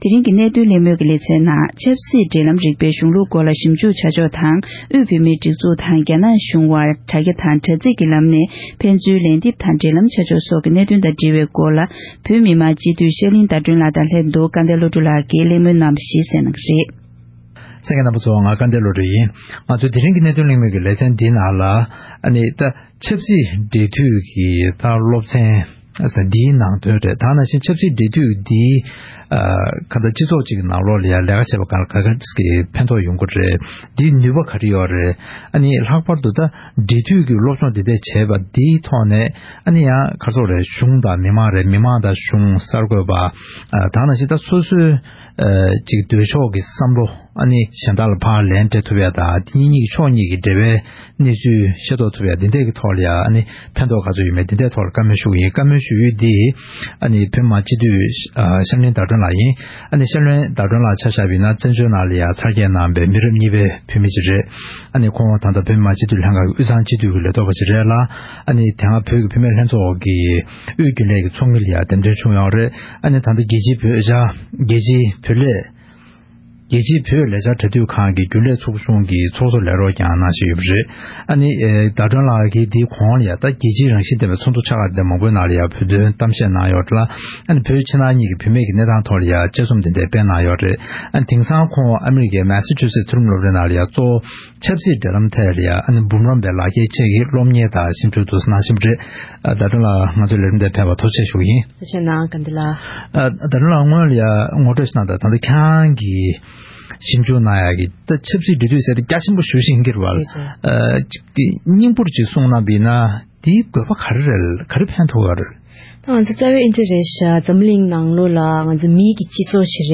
༄༅། །ཐེངས་འདིའི་གནད་དོན་གླེང་མོལ་གྱི་ལས་རིམ་ནང་།